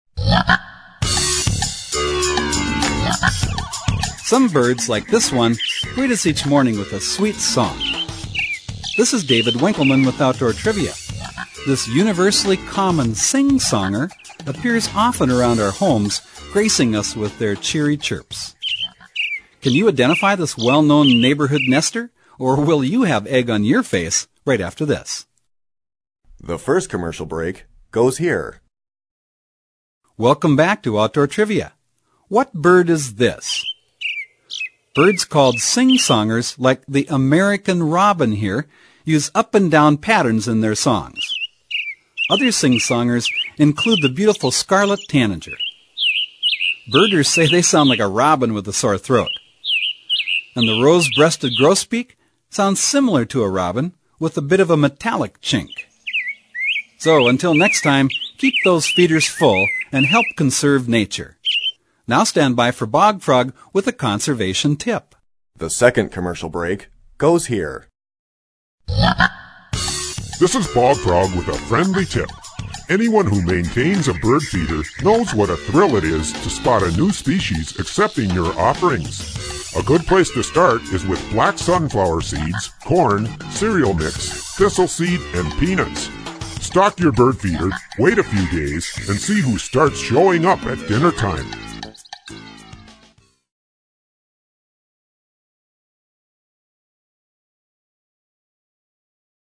Tune in and play “Name that bird call!” Unlock the enigmas behind the sounds produced by gamebirds.
In fact, the question and answer trivia format of this program remains for
bird-calls-01.mp3